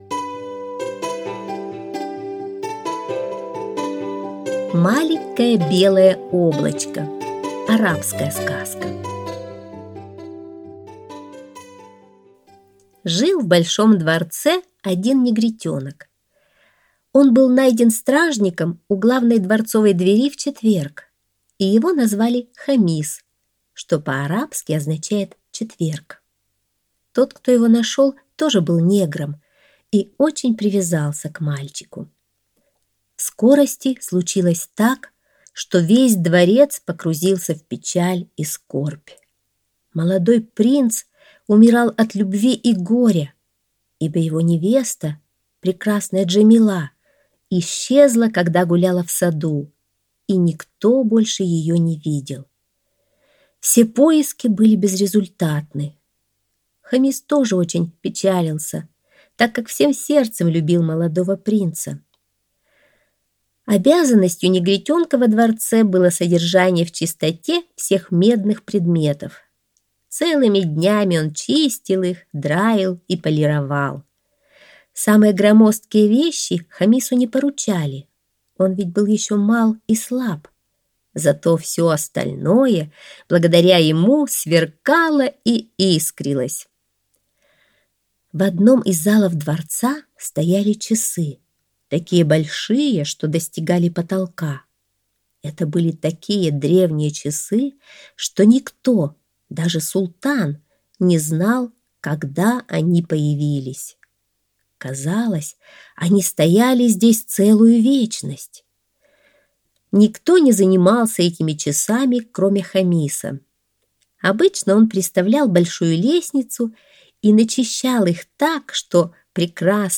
Маленькое белое облачко - арабская аудиосказка - слушать